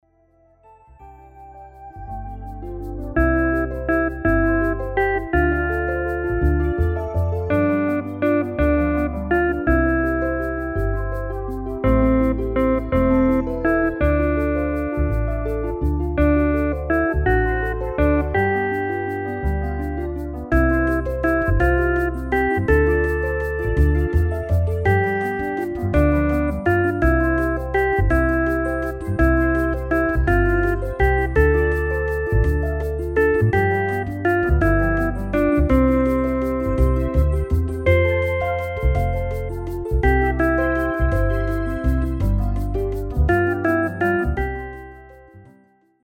Le PLAY-BACK mp3 est la version
instrumentale complète, non chantée,
NOËL